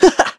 Evan-Vox-Laugh1.wav